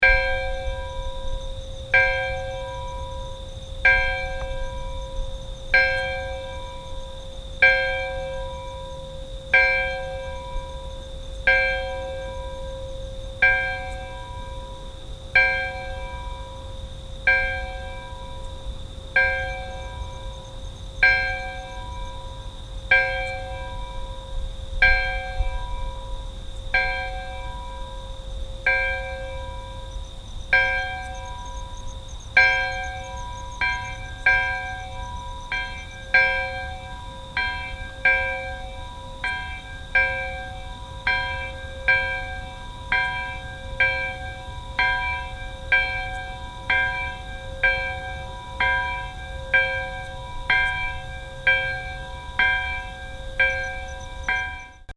Day31.Tucson, AZ
* We decided to record the Bell in the desert in the evening after it cooled off.
It was pretty quiet with the occasional car and more frequent buzz of a small plane. The incredible nighttime chorus of insects more than compensated for our ideal non-machine sound environment.
The air was thick with sound that you’d walk through like streams of water.
We decided that one of us would ring the bell and the other would walk far away and then come back.
bellbugs-az.mp3